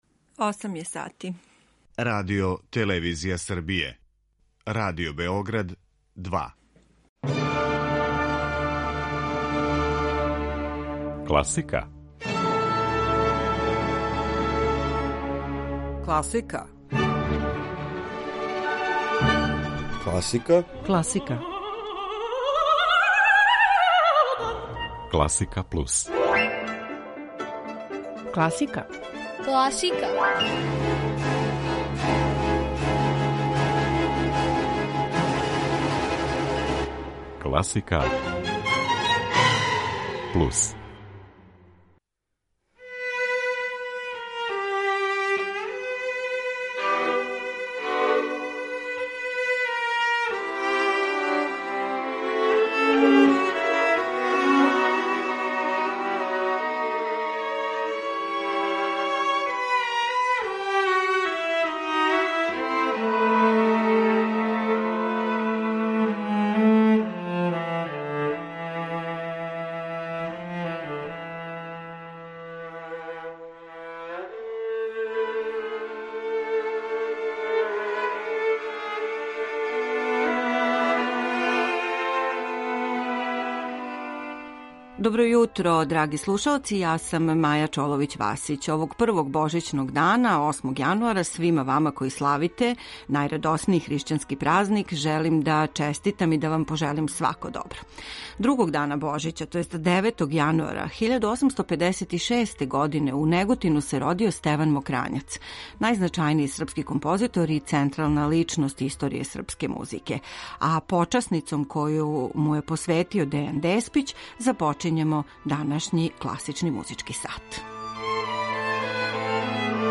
Јутро уз класику